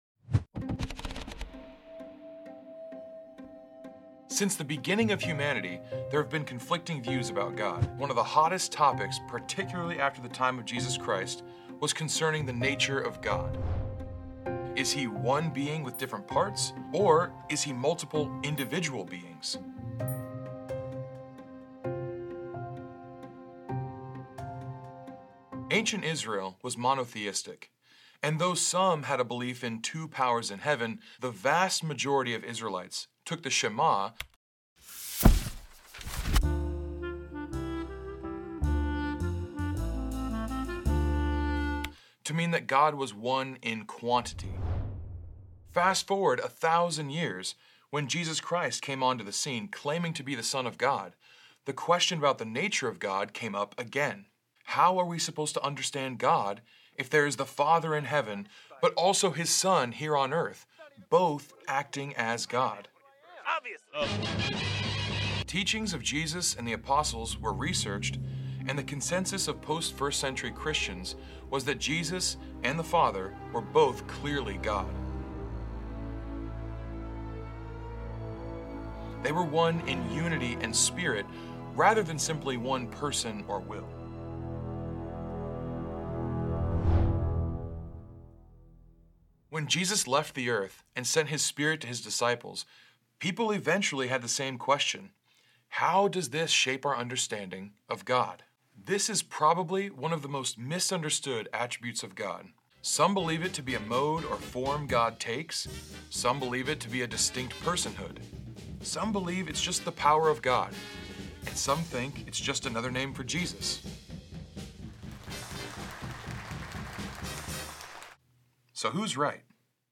We deliver a robust biblical education through quick and entertaining videos with an aim to bring the hope of the gospel into digital spaces — rife with memes, bright colors, and probably too many pop culture references.